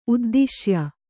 ♪ uddiśya